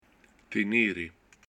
tènere soft
tenére to keep